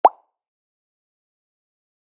pop-10.mp3